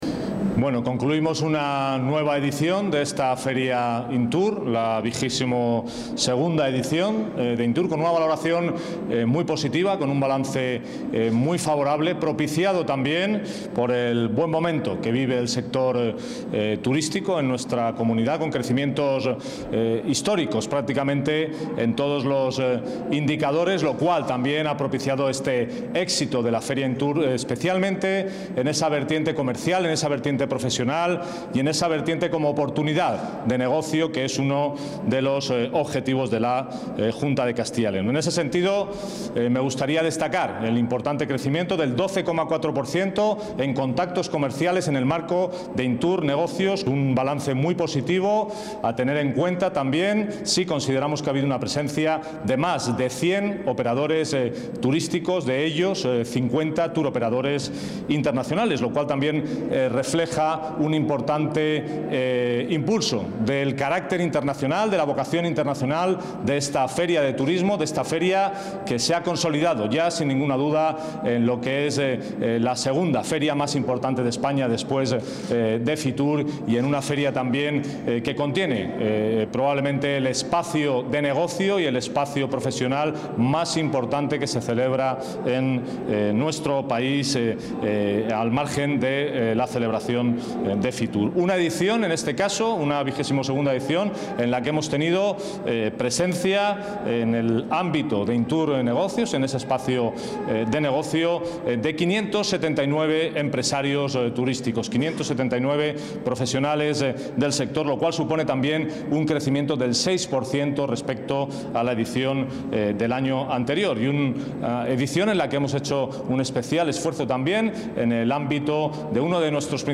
Declaraciones del director general de Turismo.